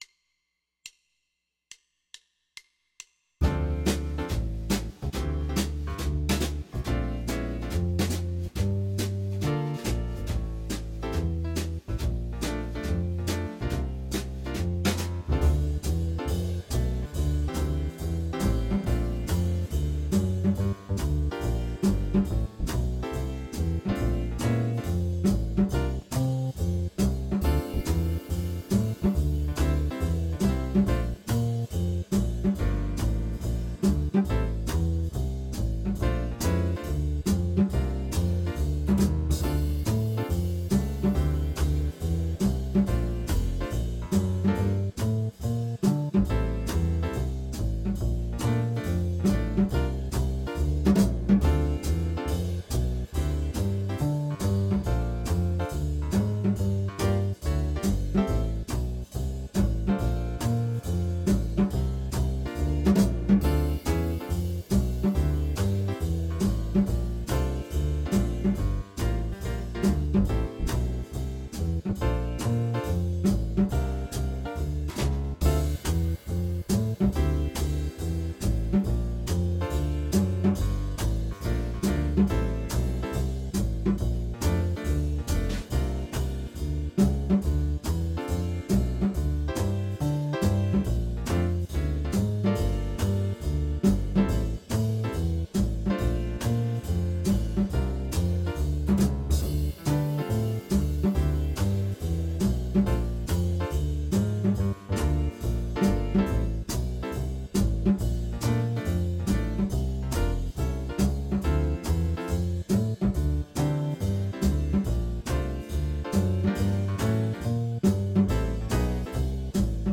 Chapter 5 The Minor Pentatonic Scale
Dm